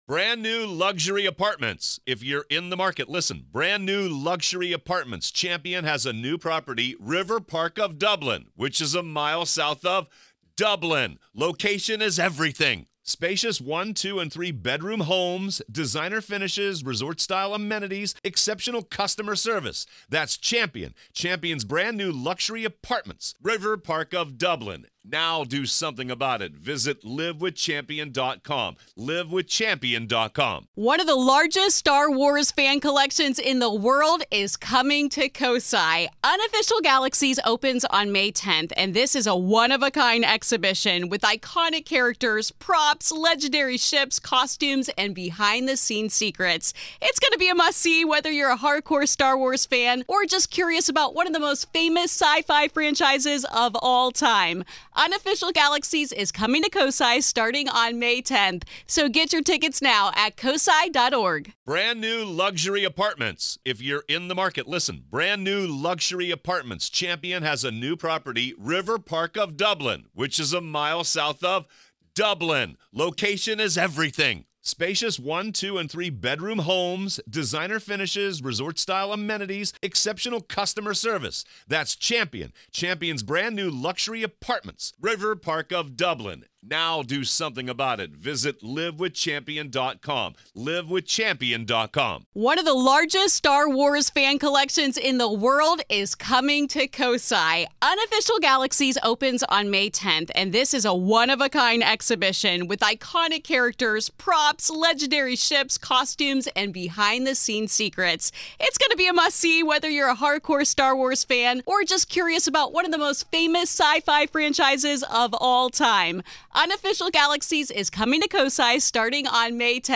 What happens when every home you move into feels just a little... off? In this unsettling story from Ohio, a young man recounts years of chilling encounters that followed his family from a humble servant’s quarters to a decrepit Victorian house—and even to his mom’s apartment....